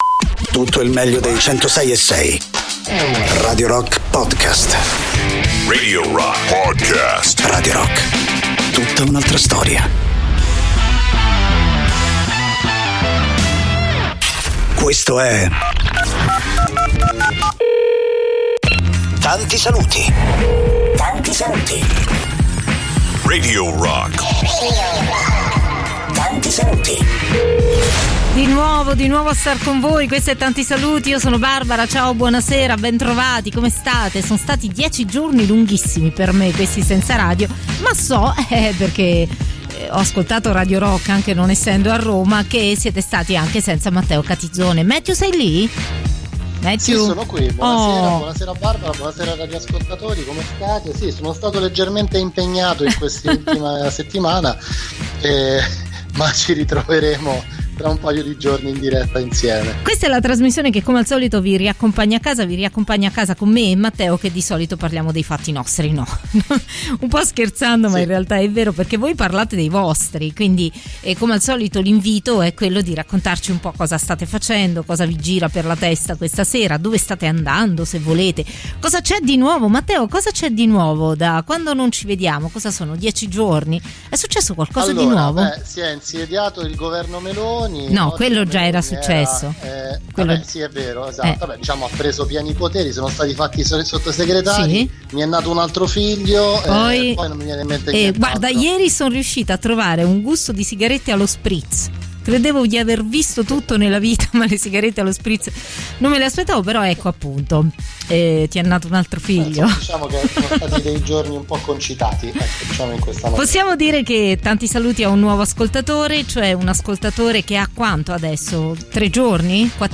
in diretta